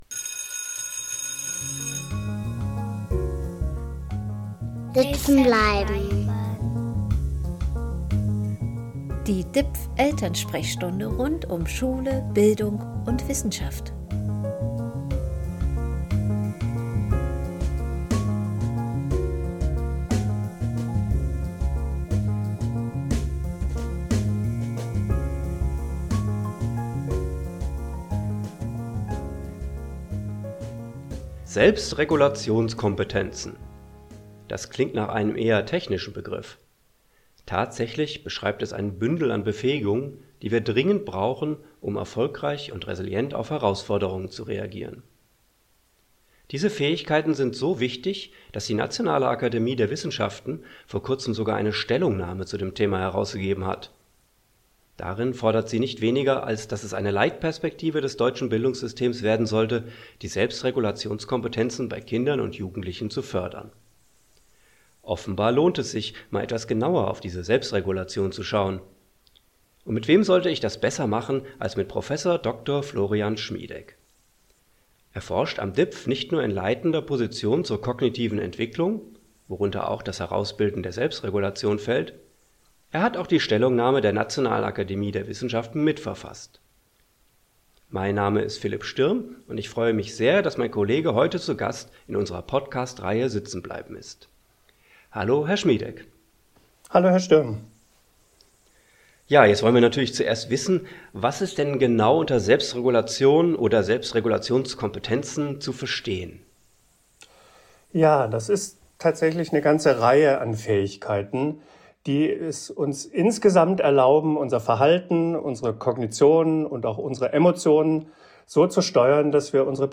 Ein Gespräch über TikTok, psychische Gesundheit und Vokabel-Lernen.